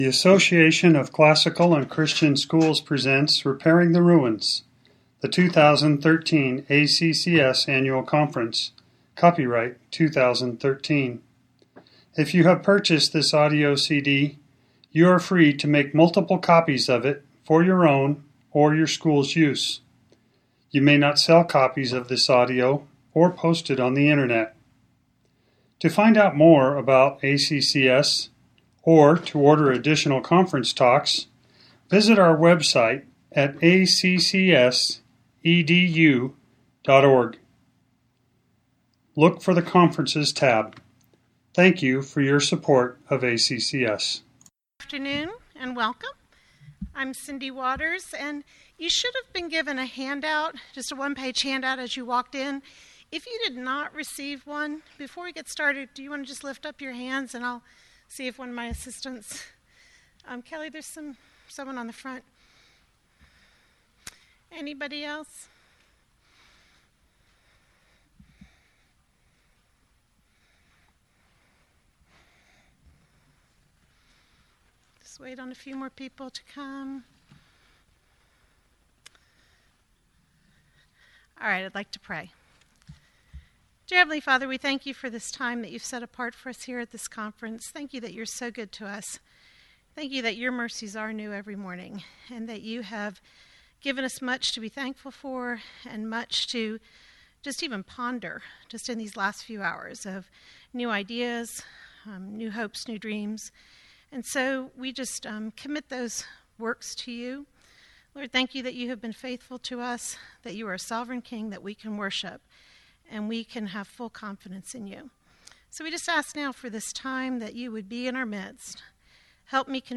2013 Workshop Talk | 0:55:17 | All Grade Levels, General Classroom